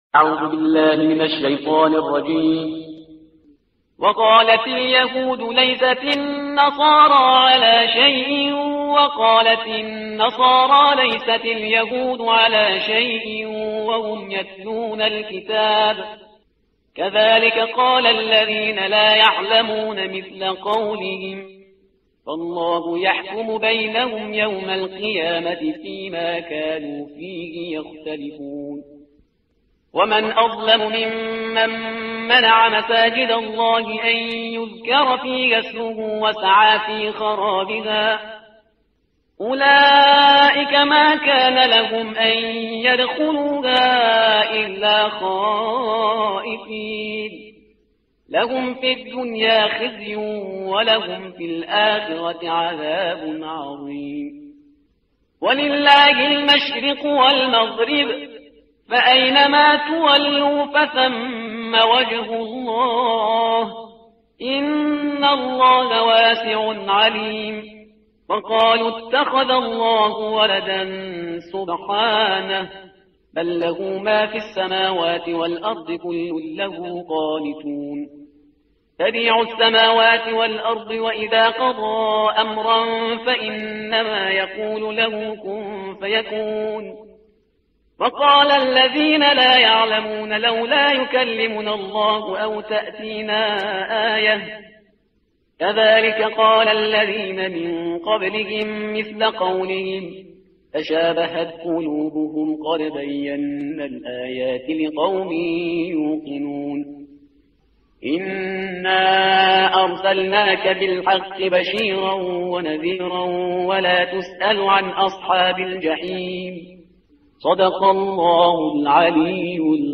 ترتیل صفحه 18 قرآن با صدای شهریار پرهیزگار
ترتیل صفحه هجدهم قرآن با صدای شهریار پرهیزگار ترتیل صفحه 18 قرآن – جزء اول سوره بقره